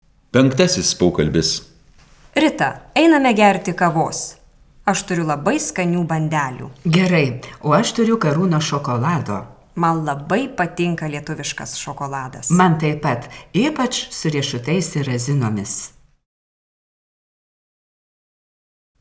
04_Dialog_5.wav